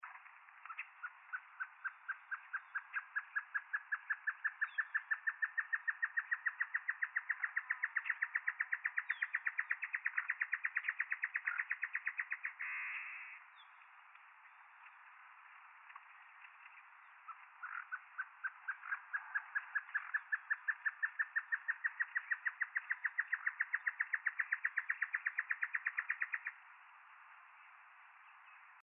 Chororó (Taraba major)
Nombre en inglés: Great Antshrike
Fase de la vida: Adulto
Localidad o área protegida: El Carmen
Condición: Silvestre
Certeza: Observada, Vocalización Grabada